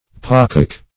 pocock - definition of pocock - synonyms, pronunciation, spelling from Free Dictionary Search Result for " pocock" : The Collaborative International Dictionary of English v.0.48: Pocock \Po"cock\, n. Peacock.